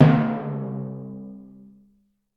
Failure Drum Sound Effect 2
cartoon drum error fail failure funny game-over humorous sound effect free sound royalty free Funny